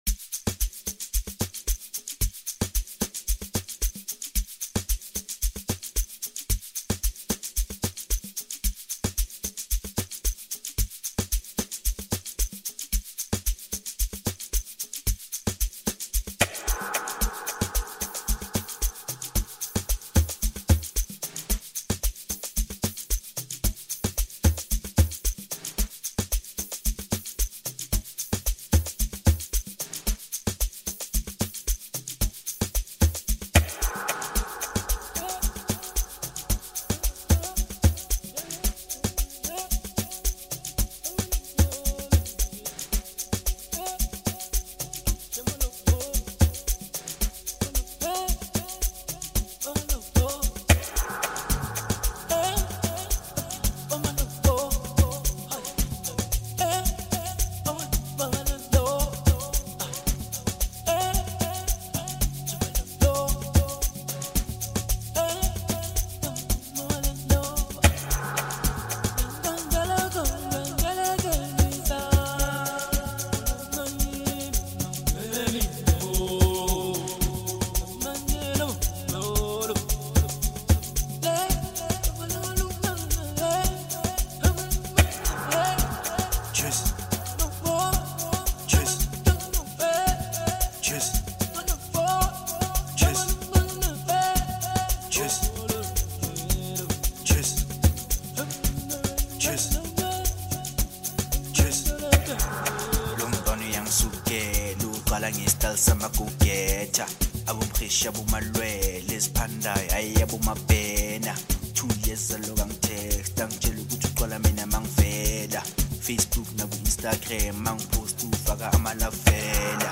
features stellar vocals
Private School Amapiano with a very soft piano baseline.